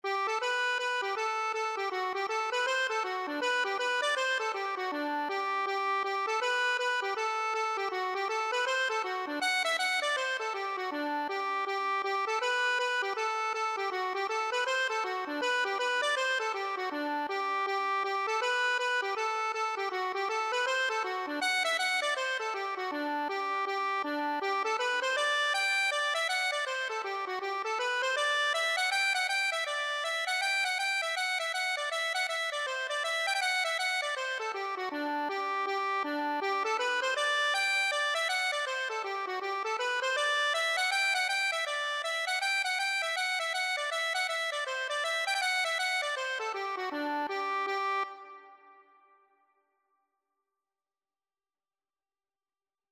Accordion version
G major (Sounding Pitch) (View more G major Music for Accordion )
4/4 (View more 4/4 Music)
Accordion  (View more Intermediate Accordion Music)
Traditional (View more Traditional Accordion Music)